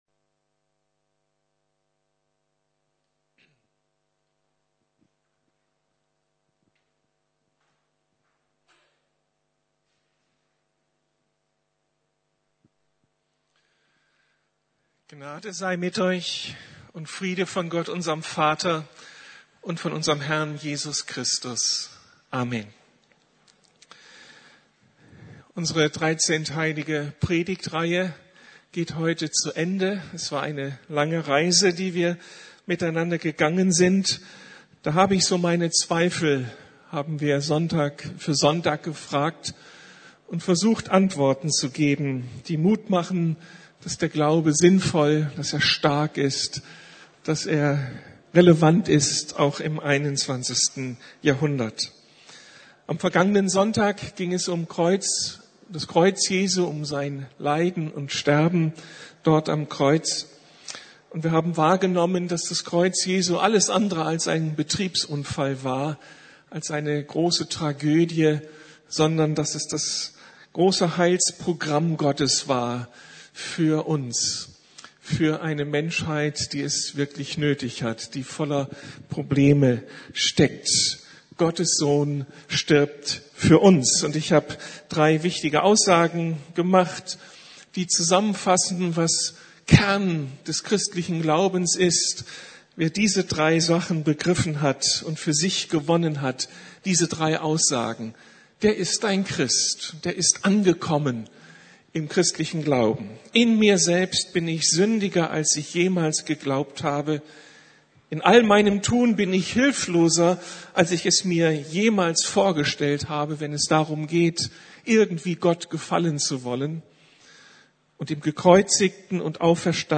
Wie kann jemand von den Toten auferstehen? ~ Predigten der LUKAS GEMEINDE Podcast